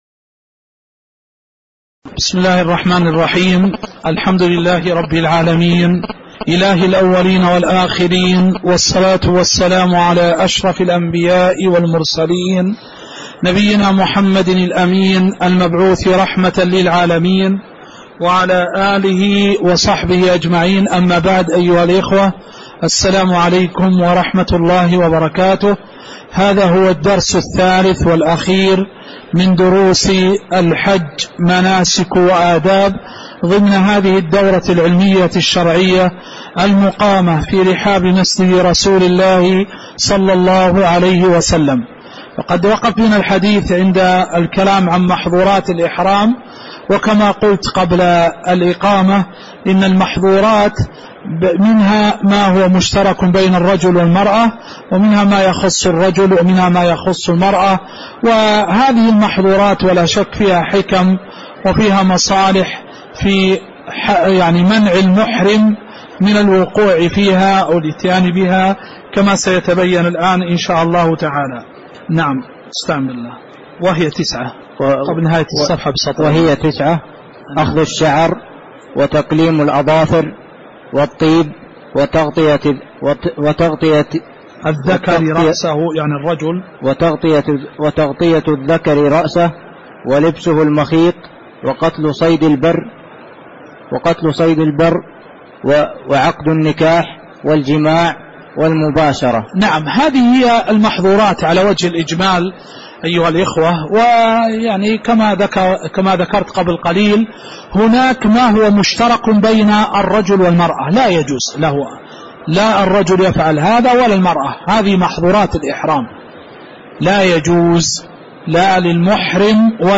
تاريخ النشر ٢٦ ذو القعدة ١٤٣٨ هـ المكان: المسجد النبوي الشيخ